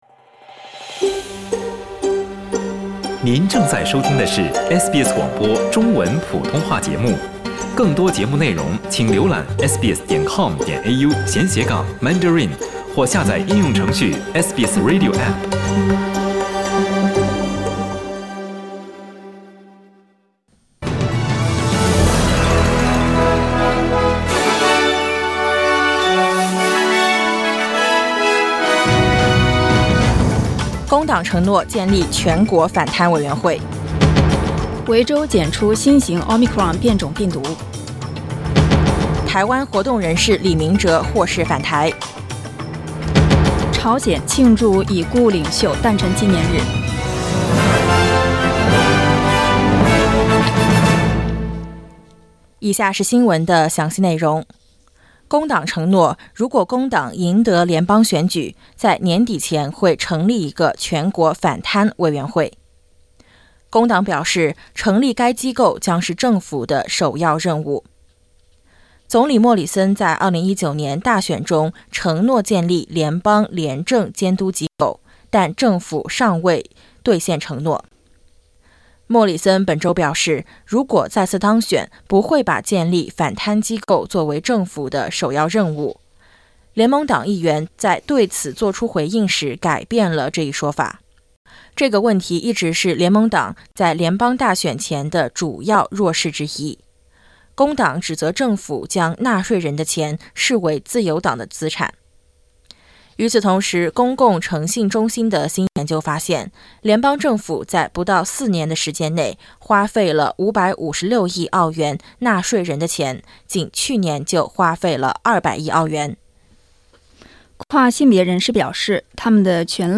SBS早新聞（4月16日）